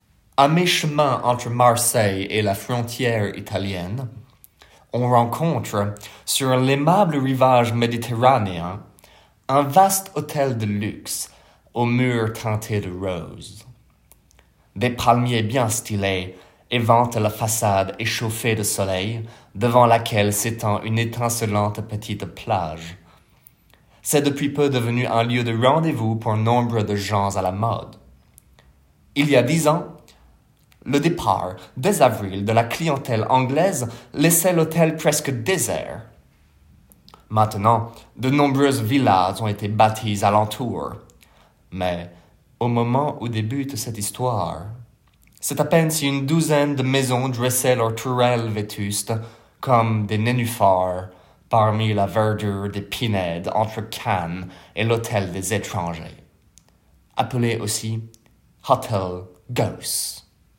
accent anglais
- Baryton